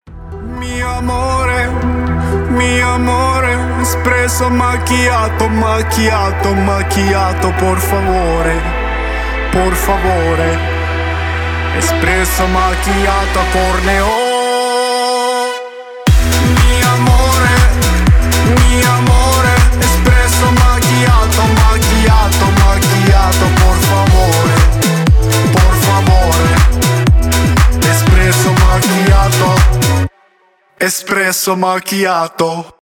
электронные